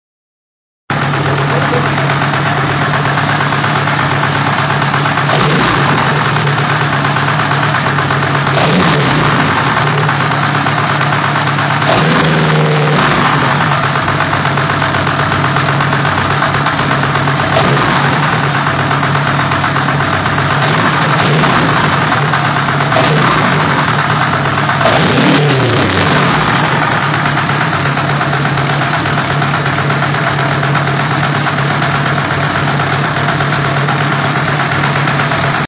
Geräusch: klappern / tackern - Motor
Wenn ich dann kurz Gas gebe und wieder los lasse, dann hört man das Tackern etwas deutlicher.
Ich habe das mal mit dem Smartphone aufgenommen: